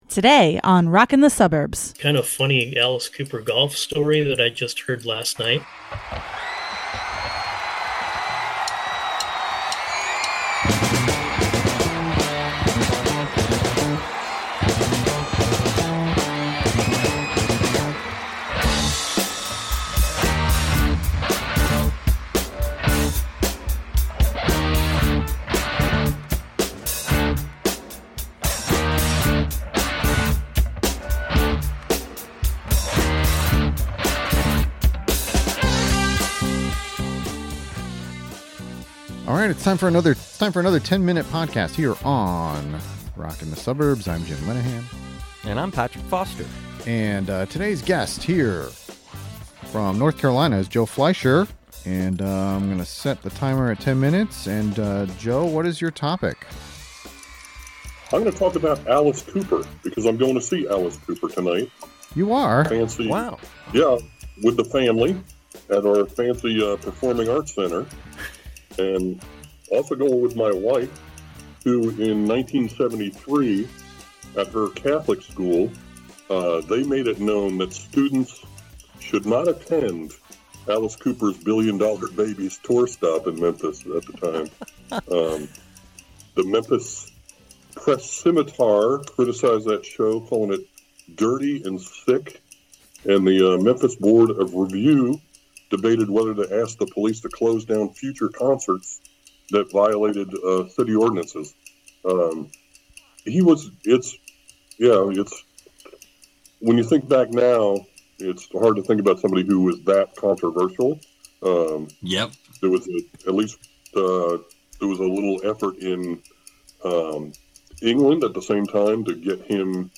We recently held a virtual podcast recording where we invited participants to come up with a topic of their choice.